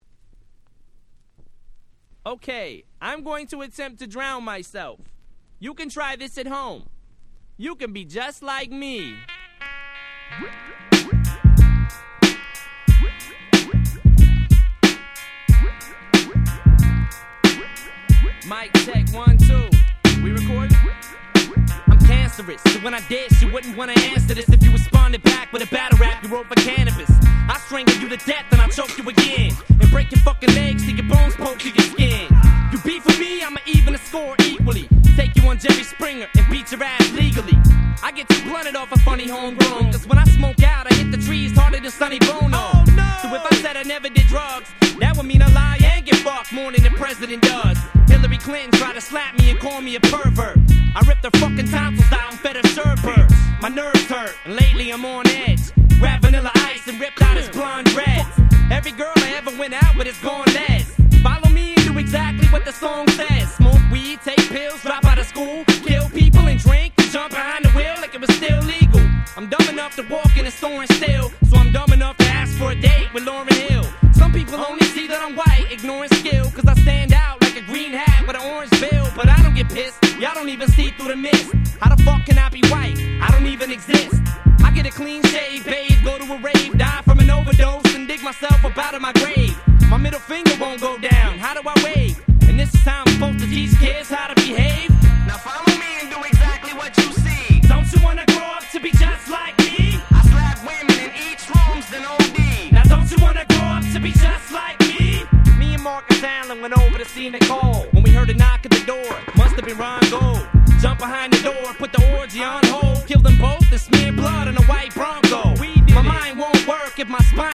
90's Boom Bap ブーンバップ